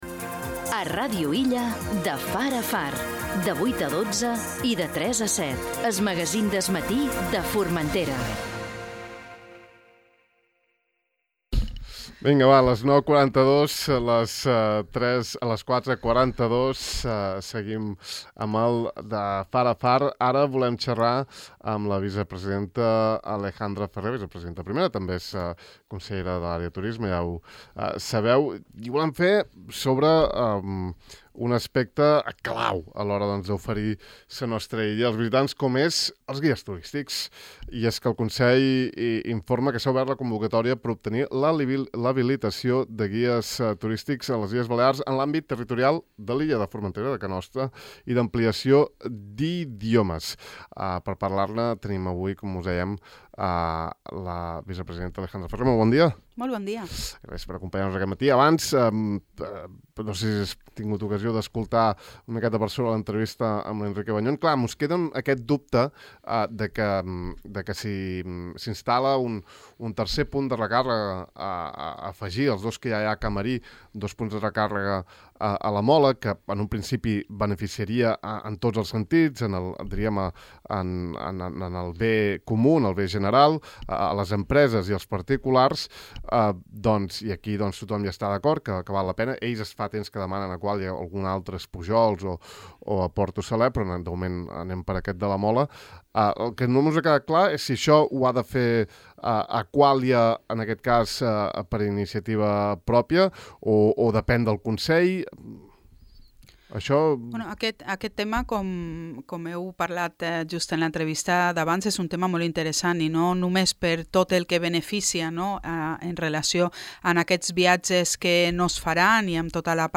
Avui hem convidat a la vicepresidenta i consellera de turisme, Alejandra Ferrer, per entrar en els detalls de la convocatòria, i un per un, ha tractat tots els temes a saber, com ara requisits, terminis, context actual, etc.